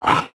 khanat-sounds-sources/sound_library/animals/monsters/mnstr15.wav at f42778c8e2eadc6cdd107af5da90a2cc54fada4c